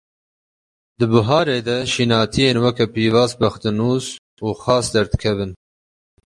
(IPA) olarak telaffuz edilir
/xɑːs/